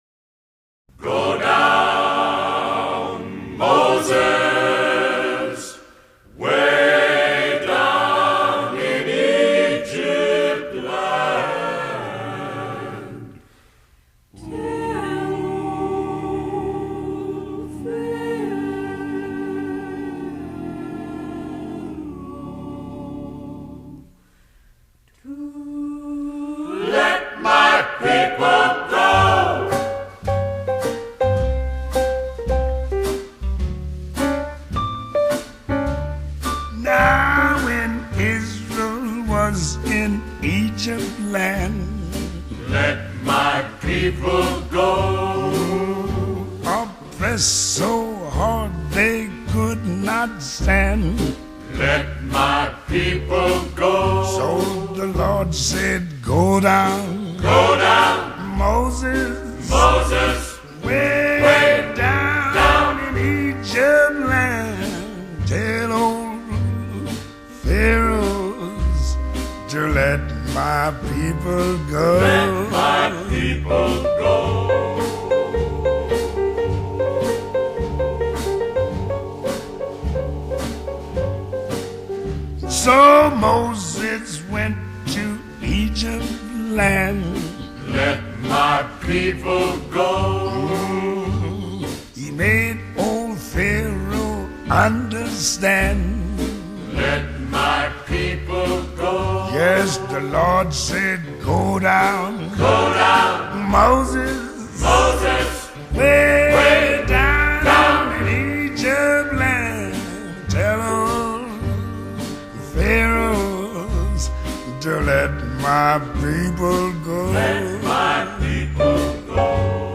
call & response